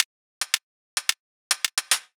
Index of /musicradar/ultimate-hihat-samples/110bpm
UHH_ElectroHatC_110-05.wav